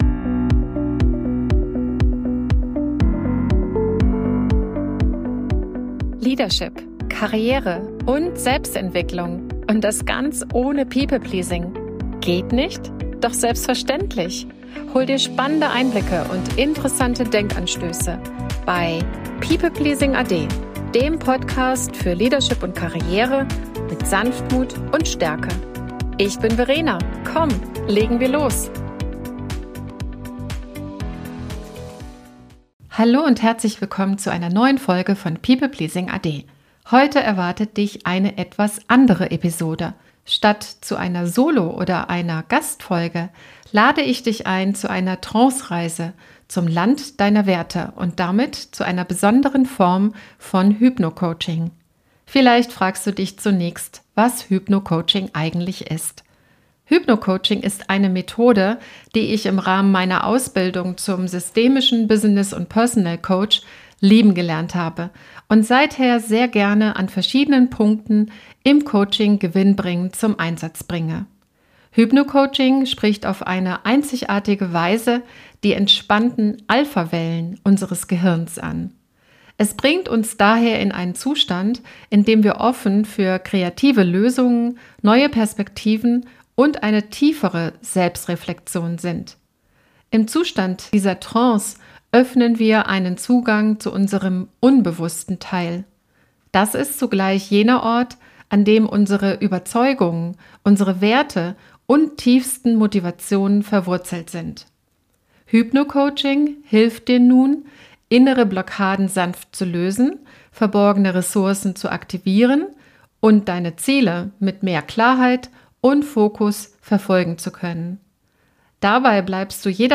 Beschreibung vor 1 Jahr In dieser Episode nehme ich dich mit auf eine Trancereise – eine inspirierende und kraftvolle Methode, die dir hilft, deine Werte zu reflektieren und dich mit ihnen zu verbinden.